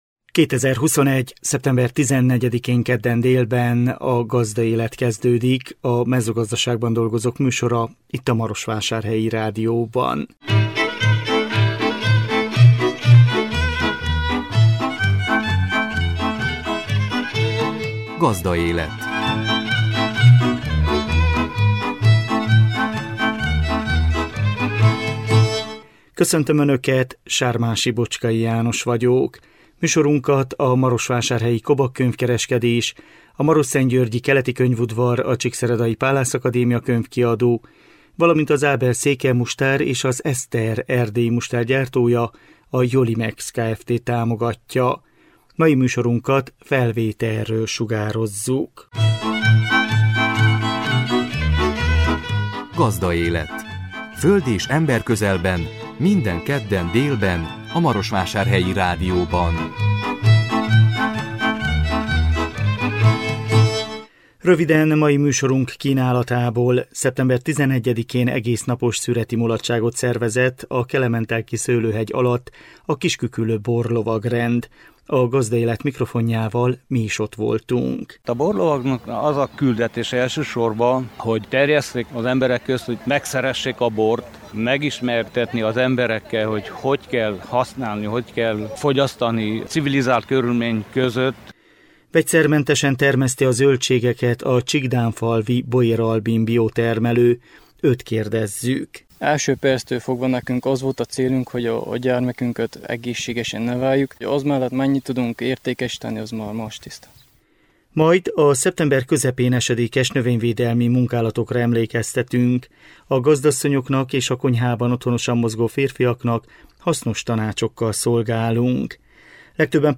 A 2021 szeptember 14-én jelentkező műsor tartalma: Szeptember 11-én egésznapos szüreti mulatságot szervezett a kelementelki szőlőhegy alatt a Kisküküllő Borlovagrend. A Gazdaélet mikrofonjával mi is ott voltunk.